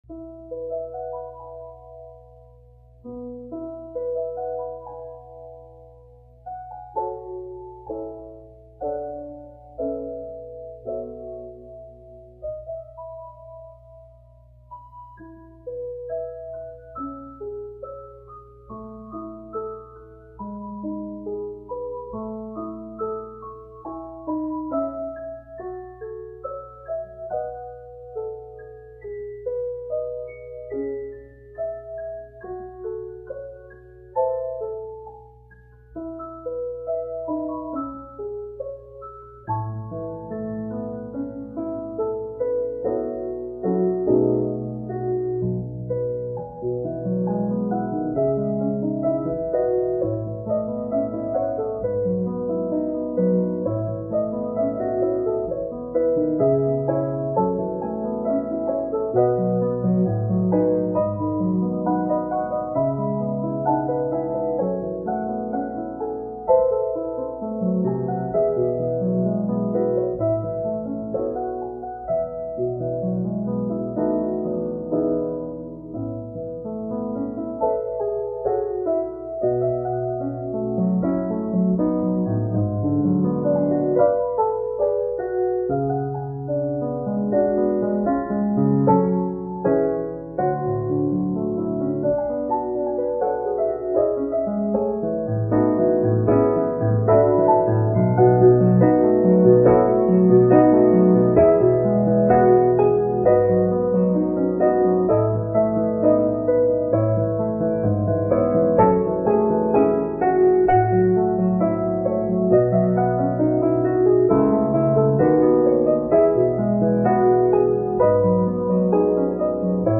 Recorded From Performance